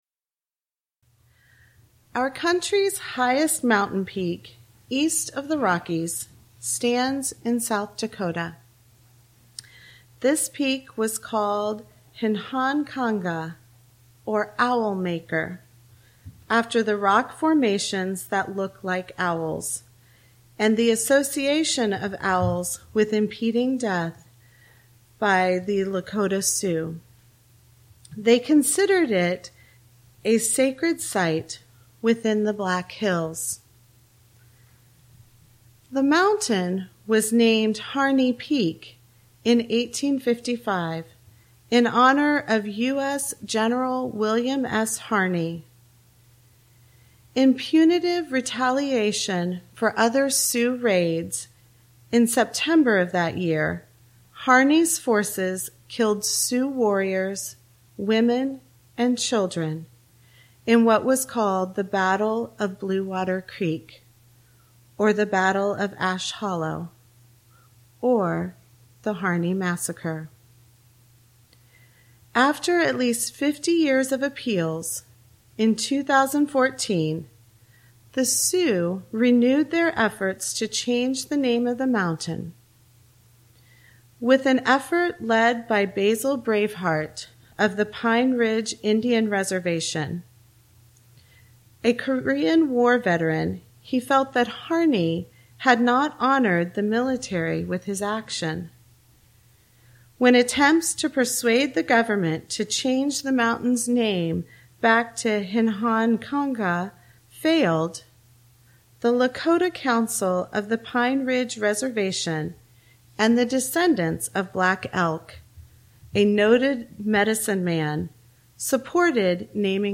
This sermon explores the interconnected nature of problem-solving by weaving together historical reconciliation and personal experience.